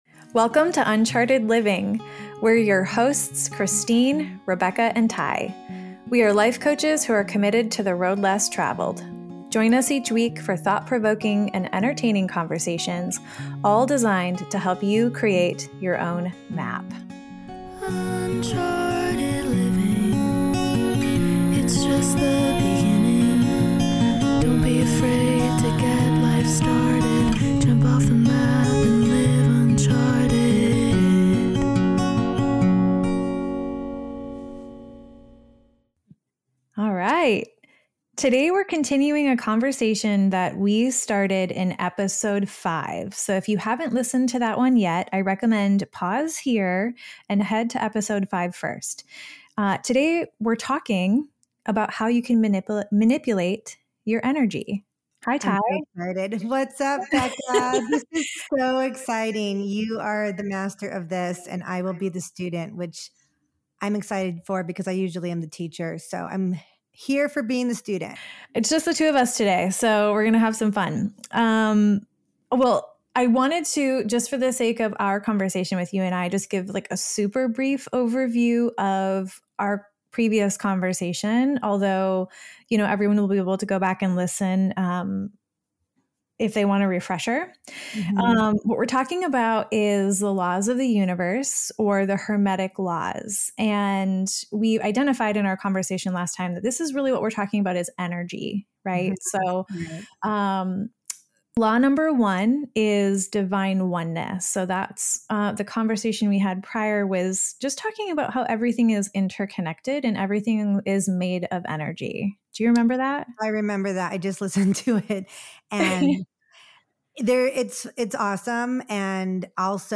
This in-depth conversation breaks down why these tools work and how we use them as life coaches.